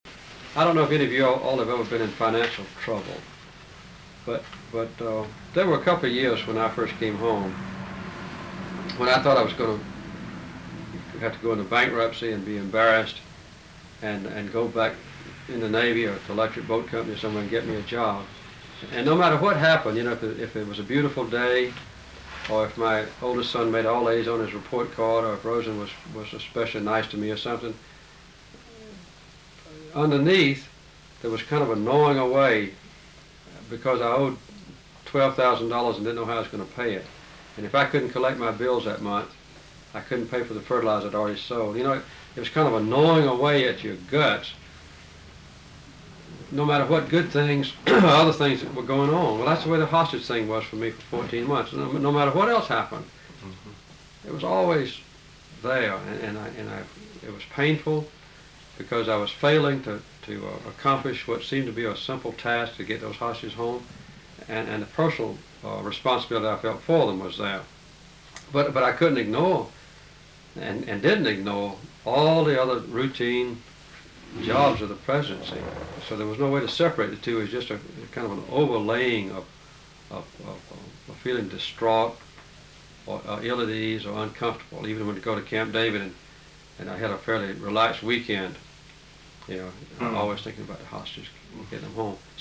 Date: November 29, 1982 Participants Jimmy Carter Associated Resources Jimmy Carter Oral History The Jimmy Carter Presidential Oral History Audio File Transcript